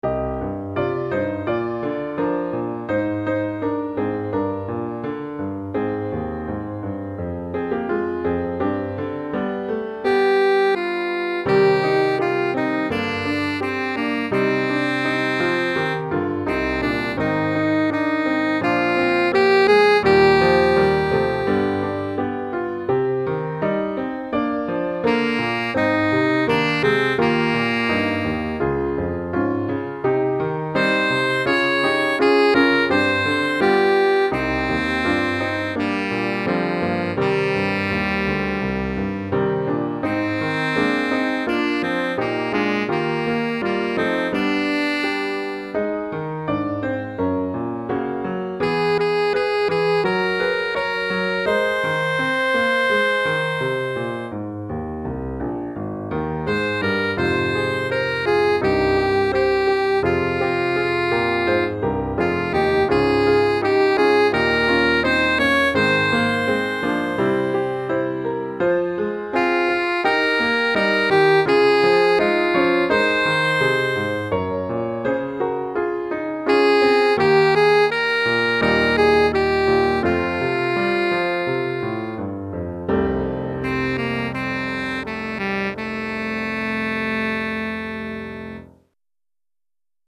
Saxophone Alto et Piano